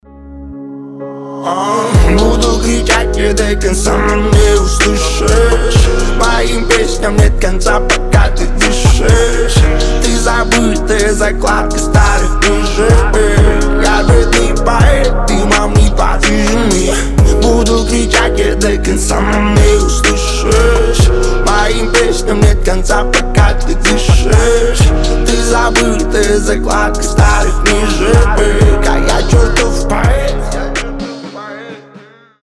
• Качество: 320, Stereo
лирика
медленные